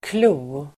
Uttal: [klo:]